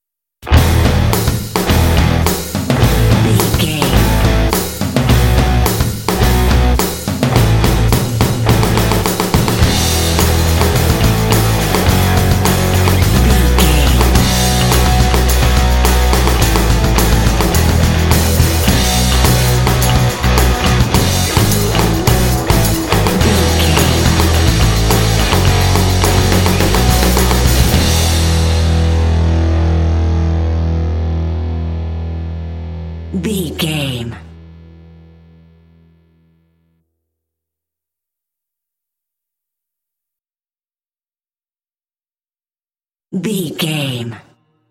This rock track is great for action and sports games.
Epic / Action
Uplifting
Ionian/Major
motivational
energetic
lively
electric guitar
bass guitar
drums
alternative rock
indie